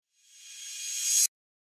Звуки битовой музыки
Здесь вы найдете мощные ритмы, минималистичные мелодии и экспериментальные звуковые текстуры.